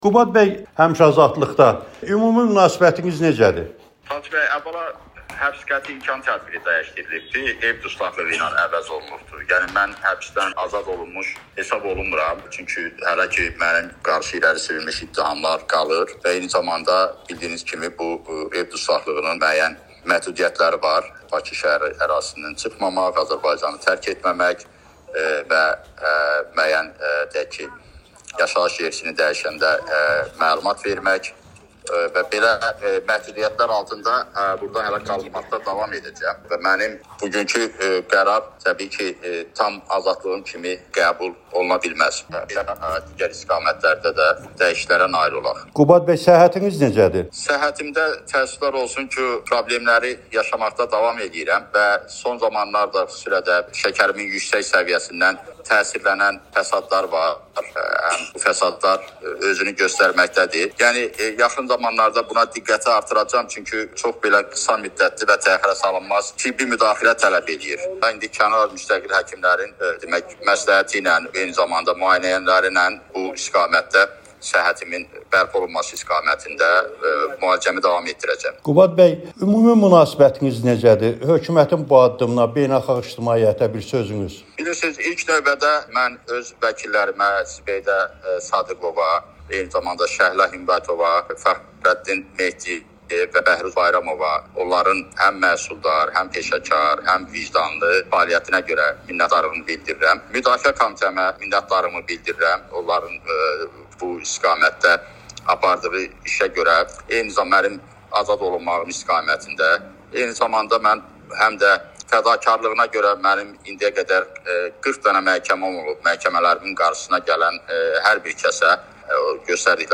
İqtisad elmləri doktoru Qubad İbadoğlunun həbsdən sonra ilk müsahibəsini təqdim edirik.